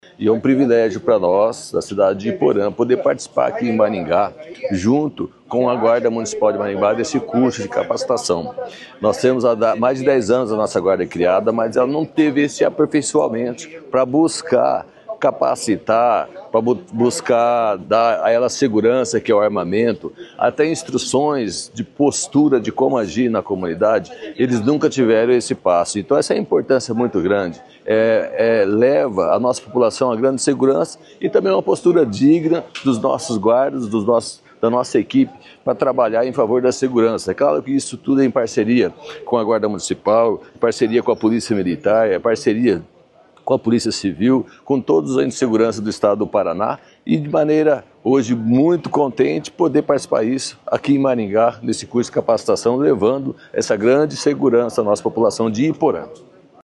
O prefeito de Iporã, Roberto da Silva, diz que é a primeira vez que os agentes da Guarda Municipal da cidade têm um curso de capacitação como este.